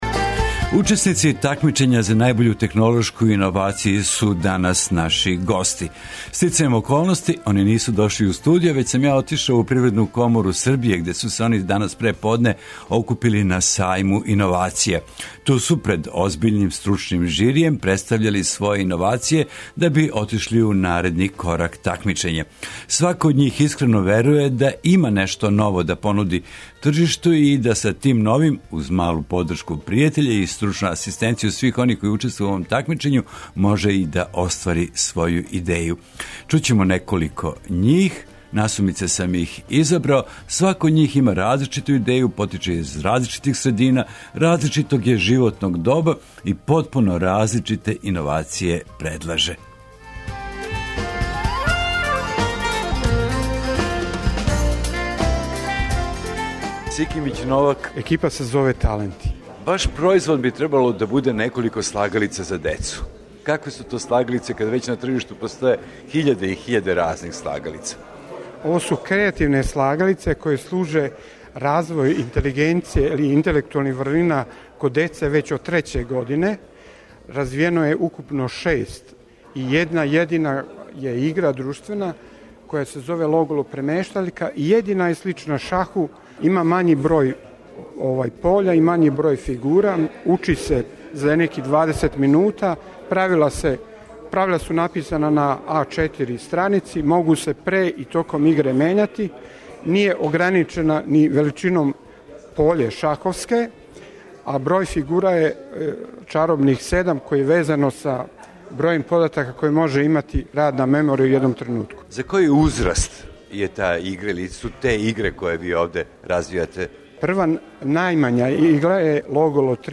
Гости емисије биће учесници Сајма иновација.